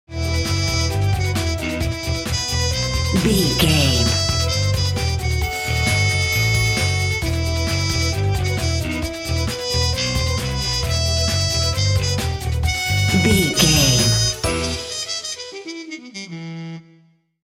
Fast paced
Aeolian/Minor
Fast
aggressive
driving
dark
dramatic
epic
energetic
groovy
frantic
suspense
horns
violin
bass guitar
drums
strings
trumpet
brass
percussion
70s